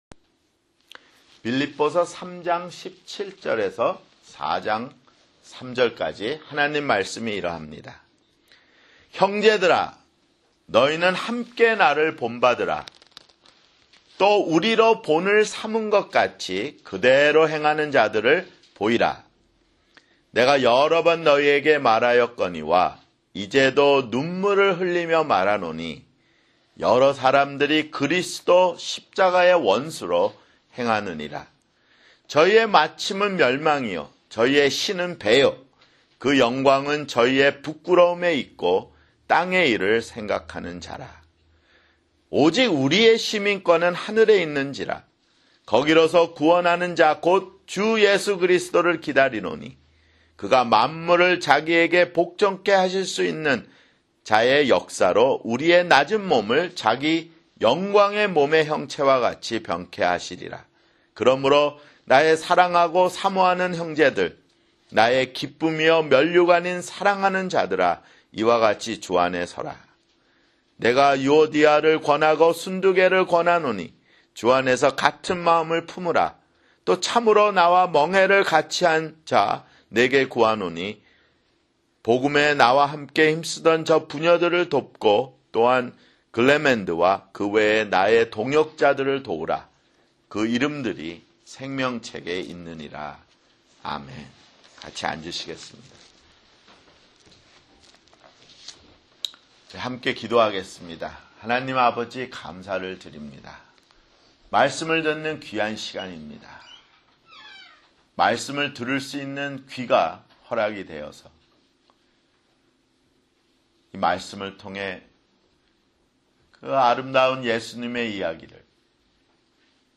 [주일설교] 빌립보서 (48)